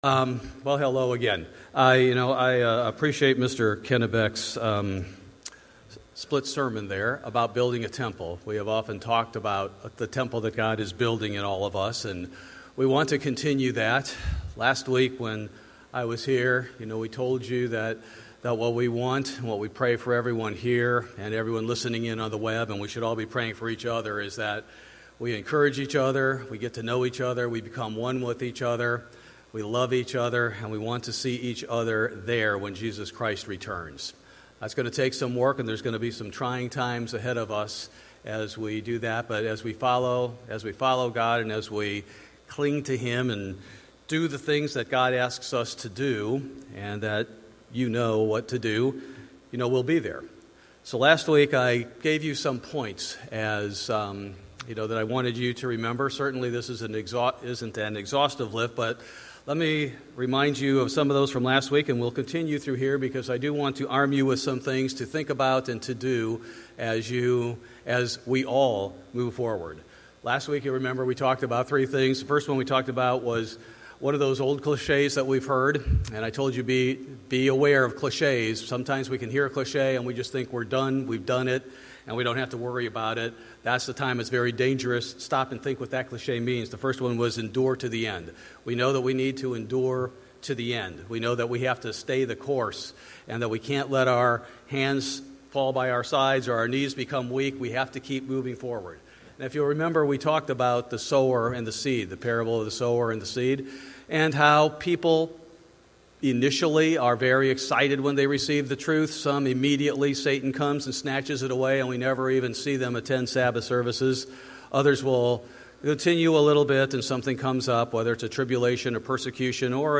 The conclusion of the pastor's final message to his congregation before moving to Cincinnati, encouraging everyone to stand strong and endure to the end.